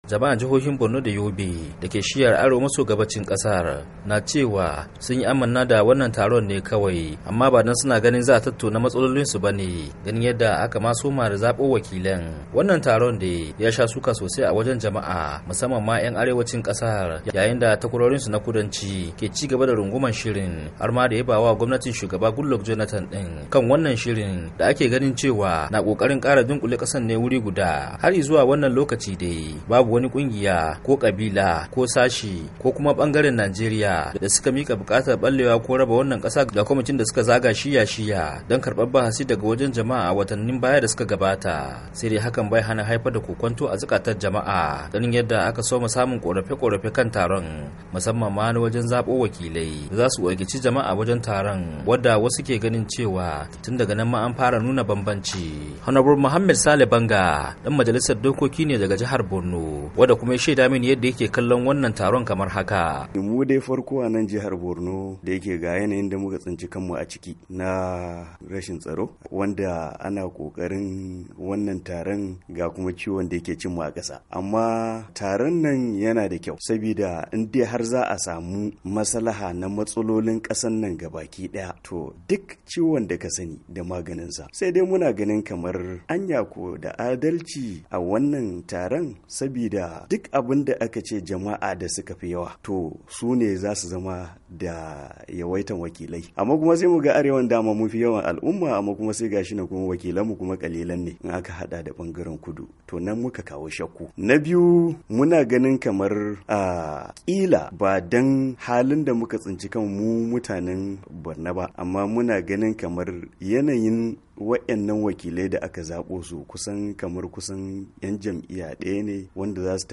Ga rahoto.